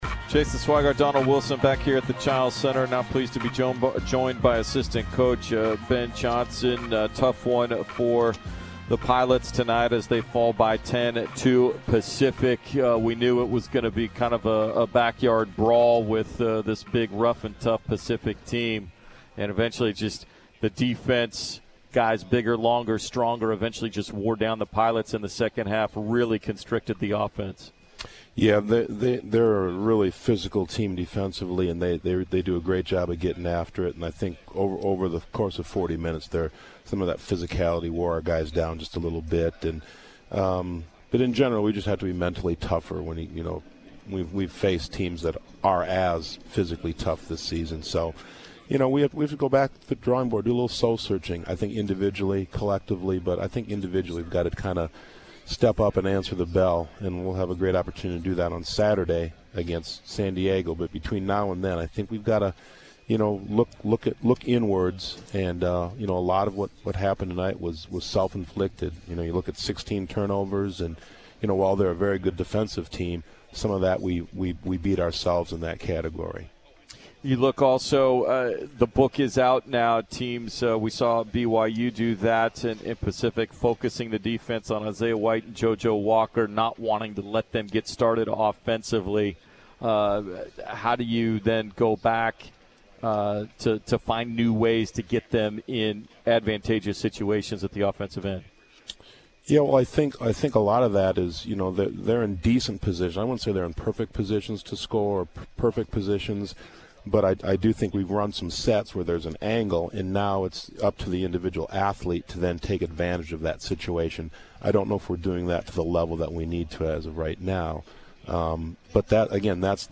Men's Hoops Post-Game Interview vs. Pacific